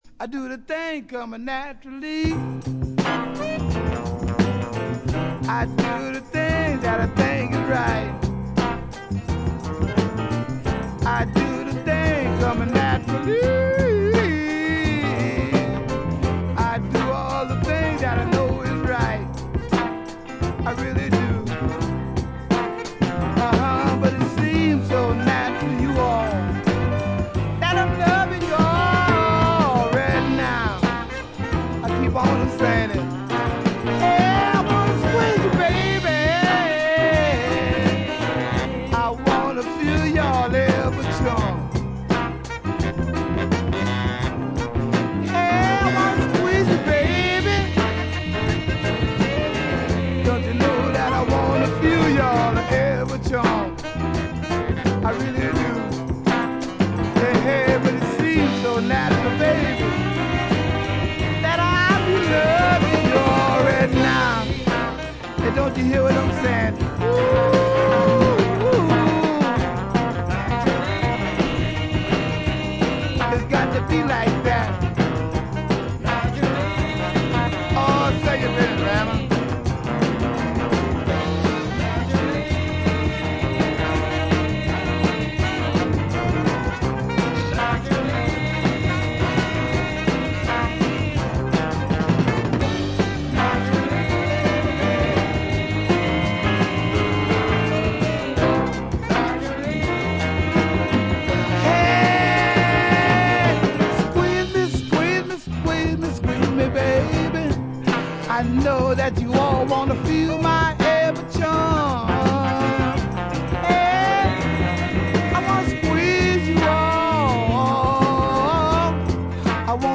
B1終盤でプツ音、気づいたのはこれぐらい。
ニューオーリンズのR&Bシンガー。
ごりごりのニューオリンズ・ファンクが特にいい感じですね。
試聴曲は現品からの取り込み音源です。